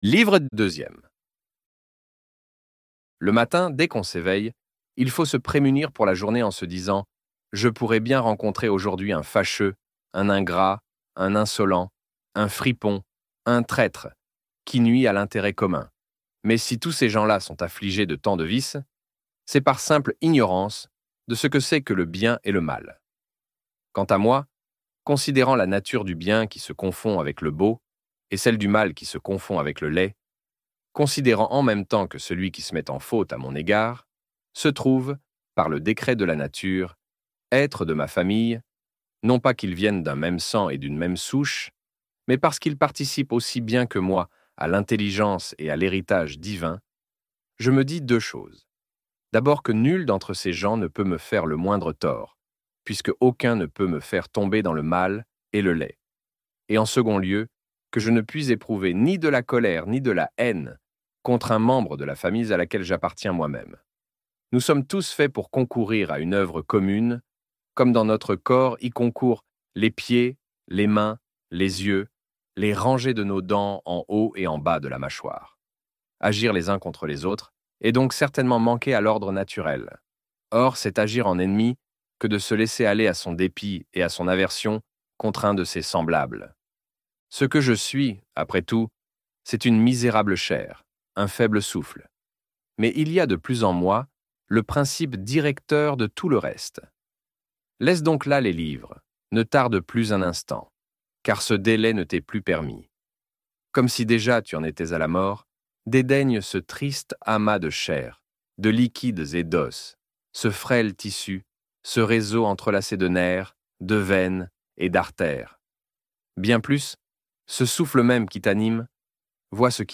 Pensées pour moi-même - Livre Audio